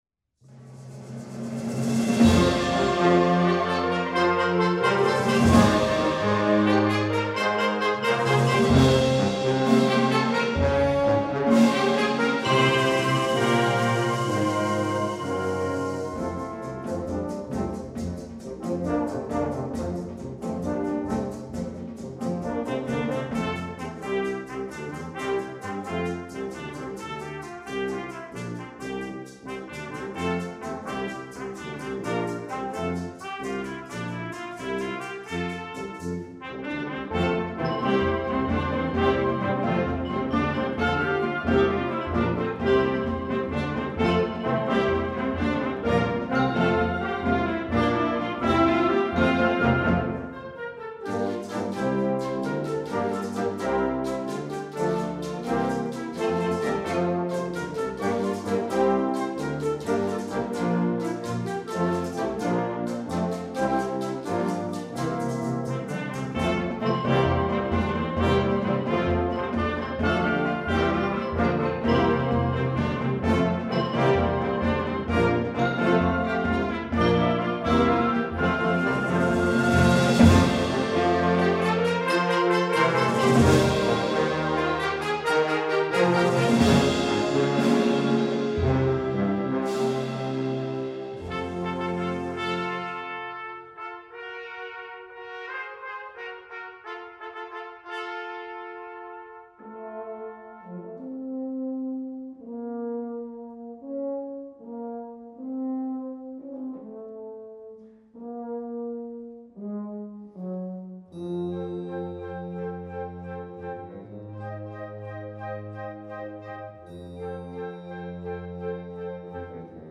Instrumentation: concert band
instructional